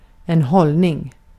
Ääntäminen
US : IPA : [ˈbɛə.rɪŋ] UK : IPA : /ˈbɛə̯ɹɪŋ/ US : IPA : /ˈbɛɹɪŋ/